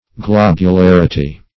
Search Result for " globularity" : The Collaborative International Dictionary of English v.0.48: Globularity \Glob`u*lar"i*ty\, n. The state of being globular; globosity; sphericity.